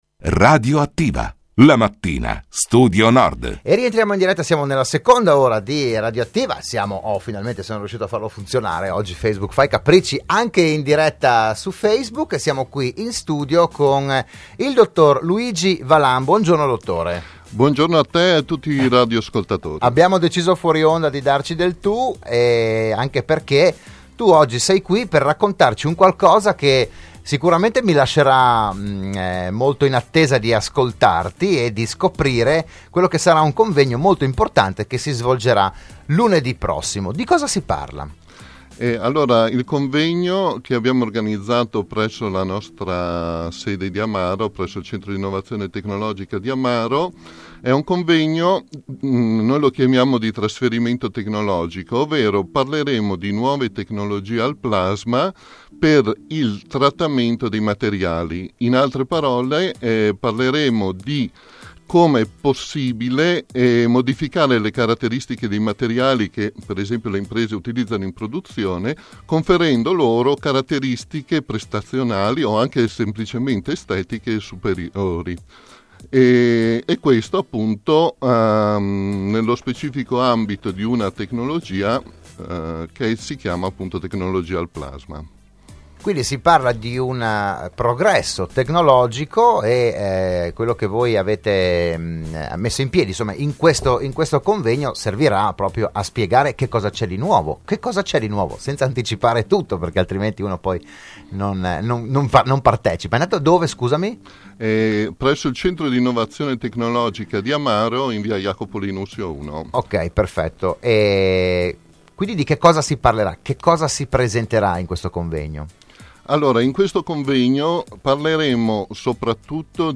Il PODCAST e il VIDEO dell'intervento a Radio Studio Nord